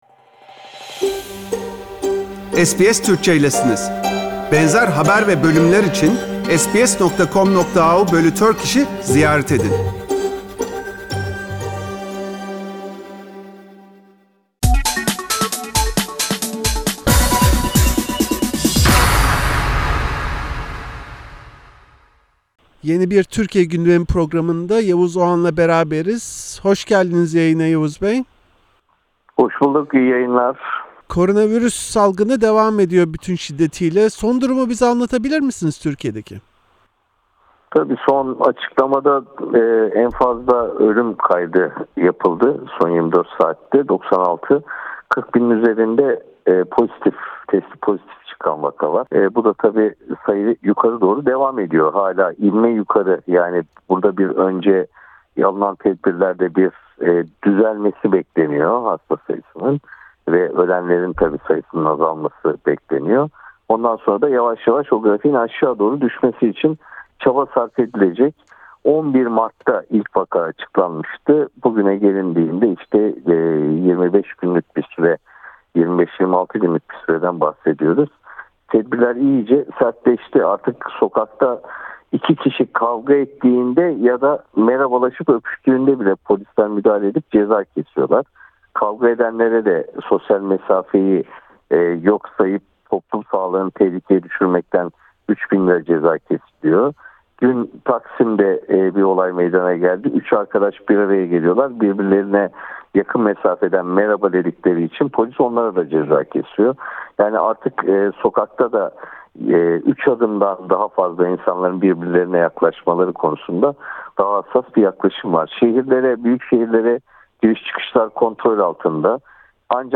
Koronavirüs salgınında hasta sayısı 40 bine ve ölenlerin sayısı bine yaklaşırken, Türkiye’de yetkilier sokağa çıkma ve mesafe kurallarına uymayanlara yönelik büyük cezalar uyguluyor. Ancak siyasi kutuplaşma bu süreçte bile gücünü kaybetmedi. Gazeteci Yavuz Oğhan anlatıyor.